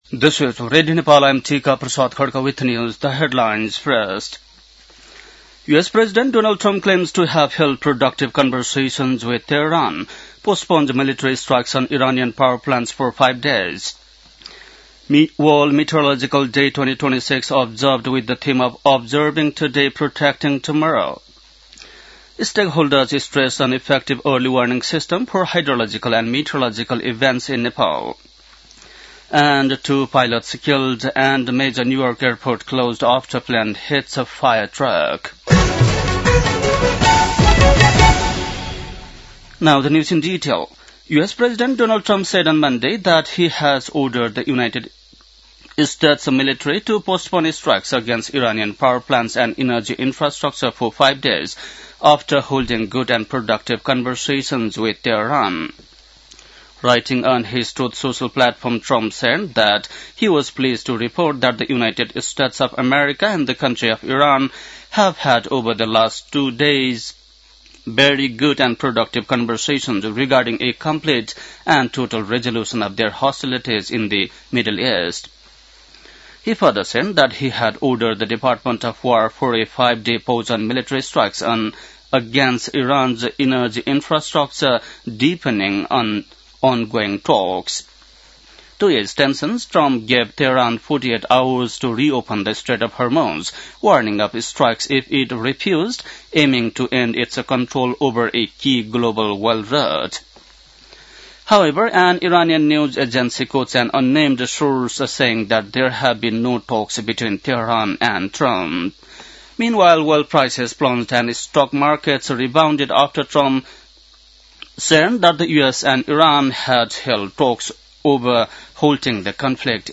बेलुकी ८ बजेको अङ्ग्रेजी समाचार : ९ चैत , २०८२